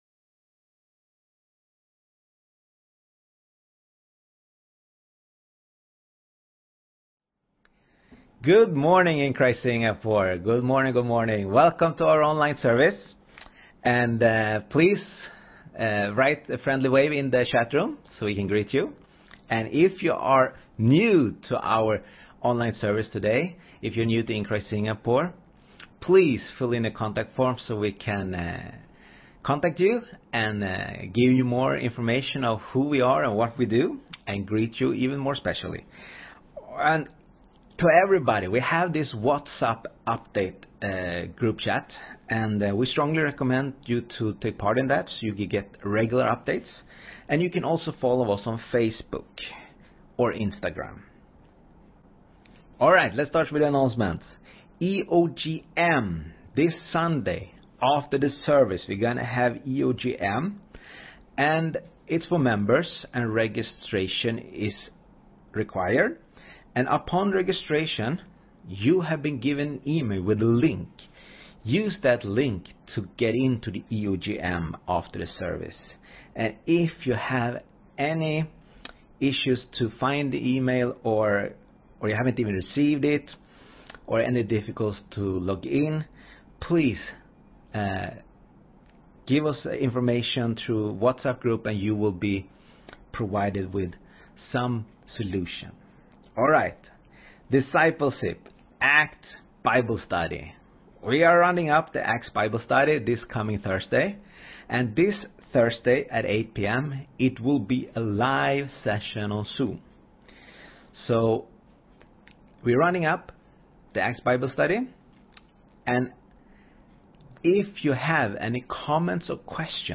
Sermon
10am service